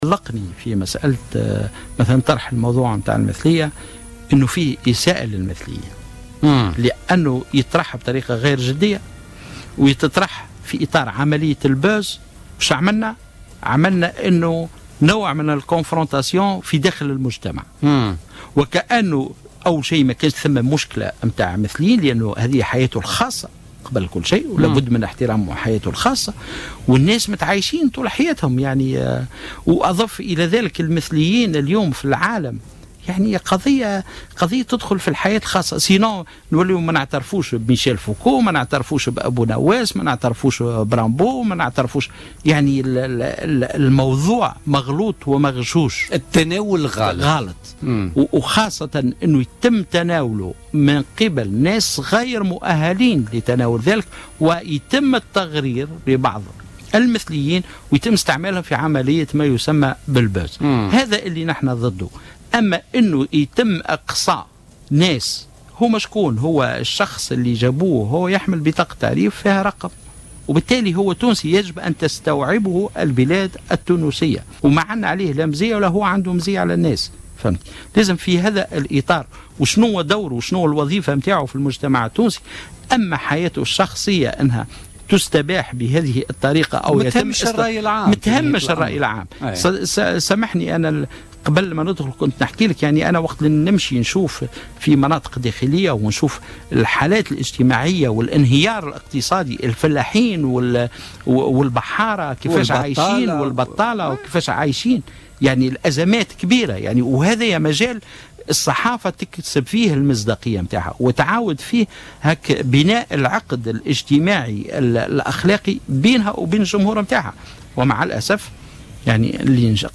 وقال في مقابلة بثتها "الجوهرة أف أم" في برنامج "بوليتيكا" اليوم الاثنين، إنه تم طرح موضوع المثلية بطريقة غير جدية وفي اطار عملية "الفرقعة" ما أفرز نوعا من المواجهة داخل المجتمع الواحد، بحسب تعبيره.